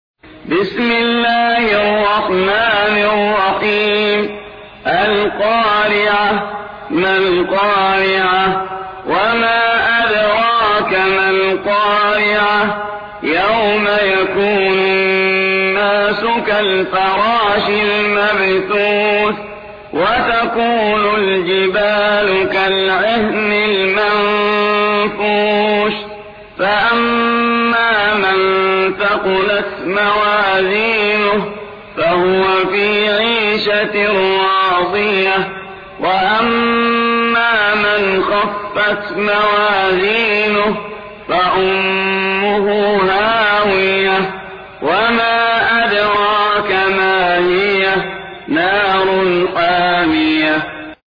101. سورة القارعة / القارئ